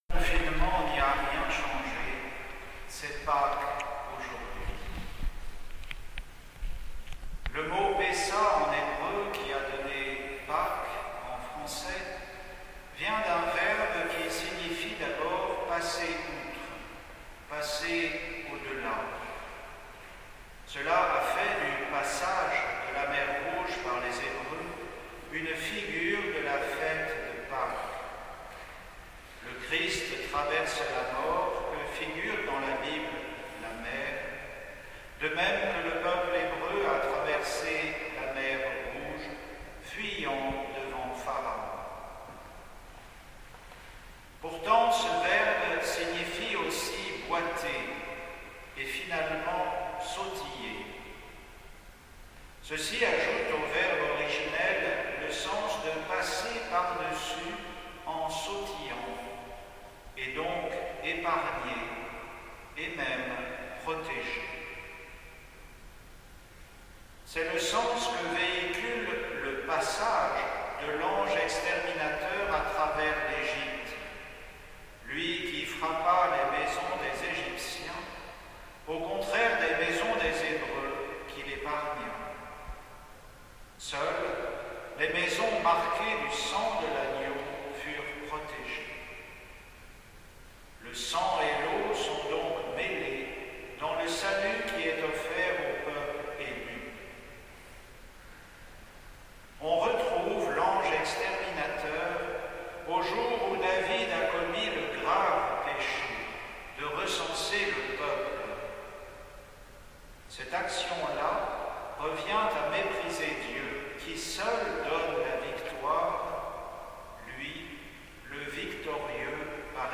Homélie de Pâques, 12 avril 2020